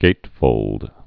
(gātfōld)